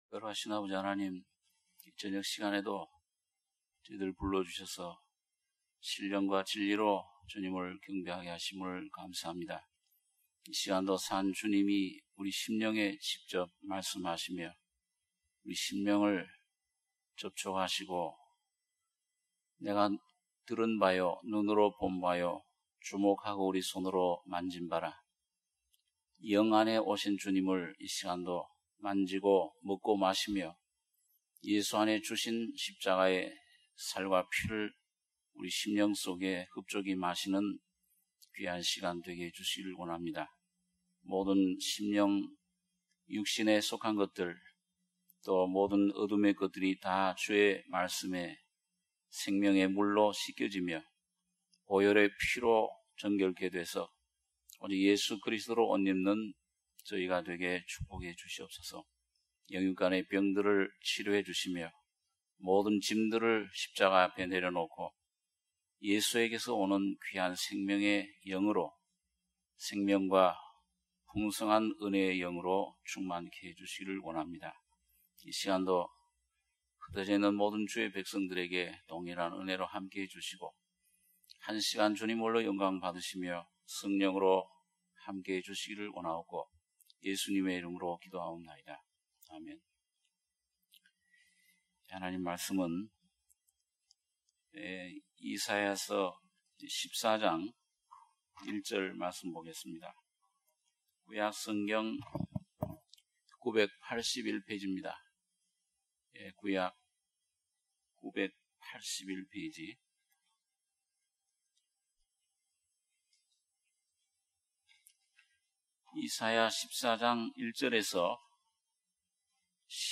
수요예배 - 이사야 14장 1절-11절